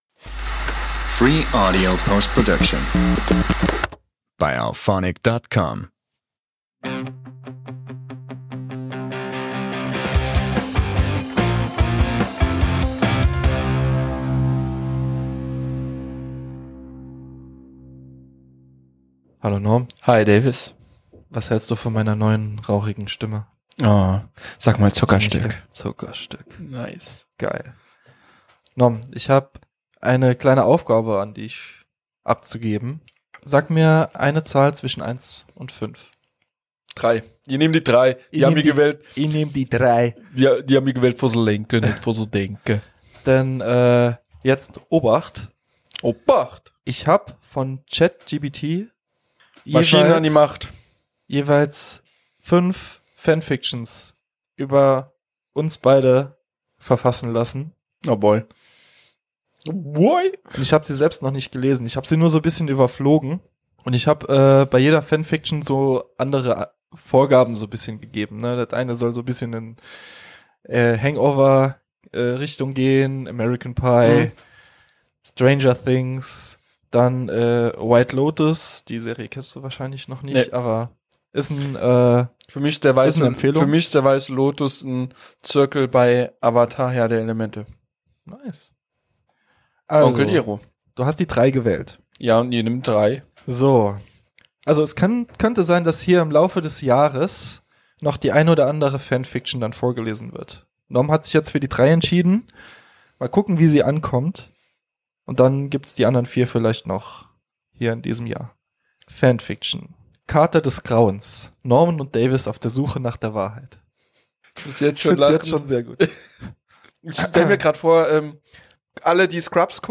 In dieser Folge stellen wir uns einer ganz besonderen Herausforderung: Wir lesen uns gegenseitig Geschichten vor, die ChatGPT extra für uns verfasst hat – inspiriert von Hangover, White Lotus und einer Prise Mystery.